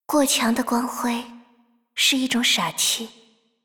挑衅语音